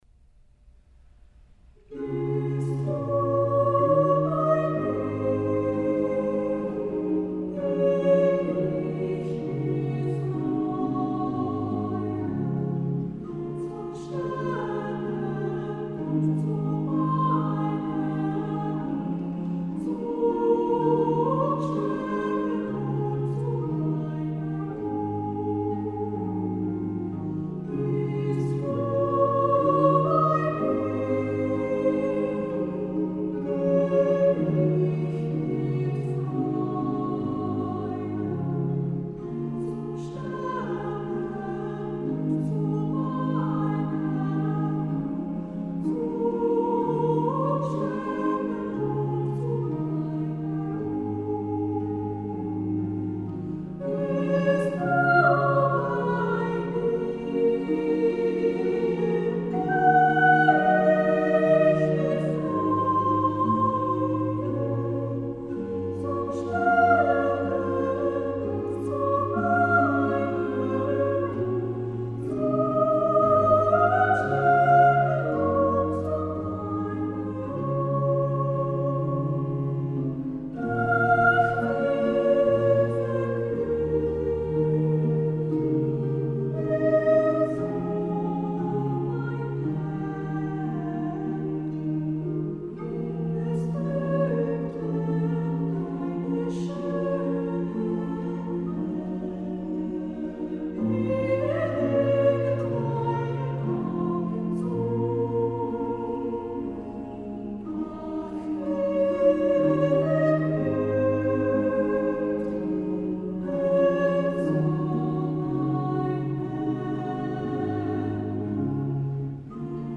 Accompaniment:      A Cappella
Music Category:      Choral
and can feature a soprano or treble soloist.